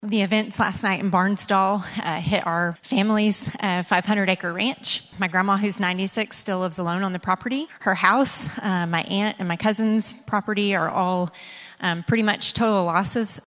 CLICK HERE to listen to commentary from State Senator Carri Hicks.